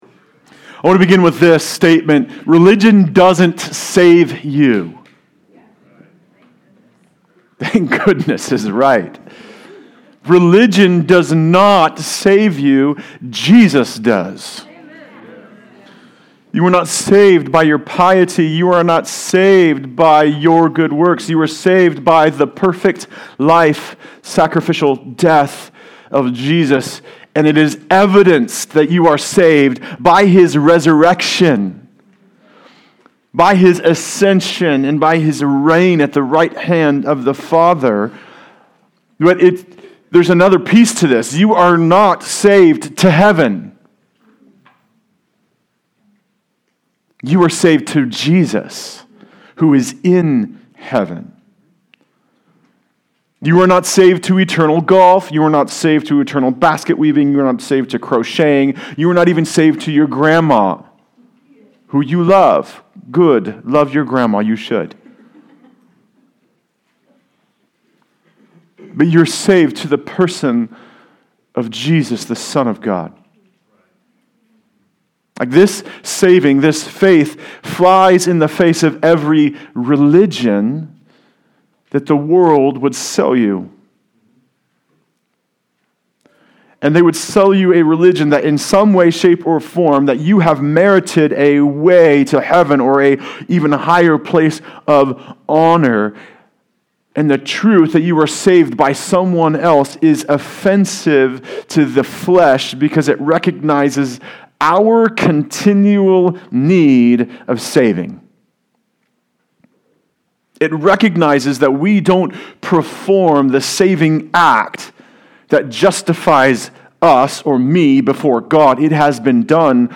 Series: LUKE: Good News for All People Passage: Luke 12:1-12 Service Type: Sunday Service